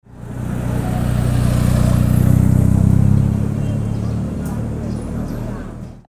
Large-motorcycle-passing-through-urban-street-sound-effect.mp3